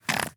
Minecraft Version Minecraft Version snapshot Latest Release | Latest Snapshot snapshot / assets / minecraft / sounds / mob / fox / aggro7.ogg Compare With Compare With Latest Release | Latest Snapshot